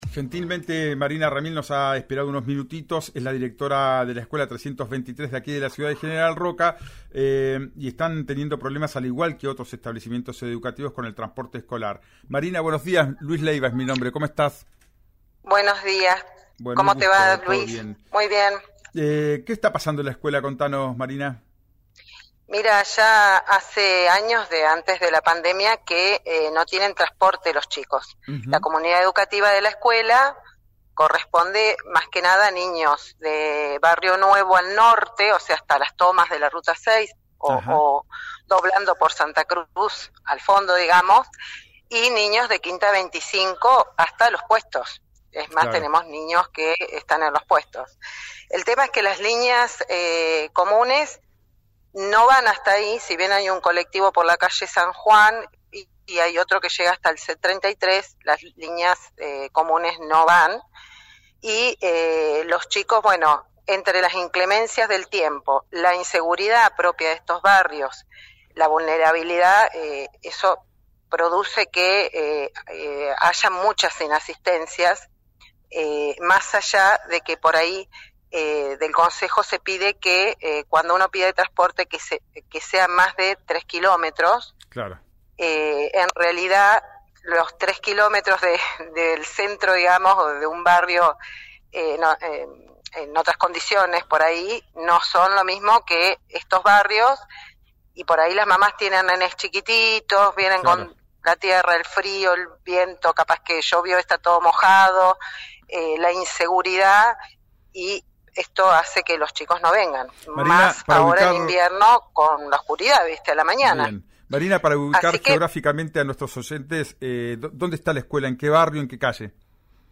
directora del colegio en «Ya Es Tiempo», por RÍO NEGRO RADIO